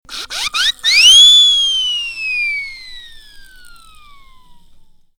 clock12.mp3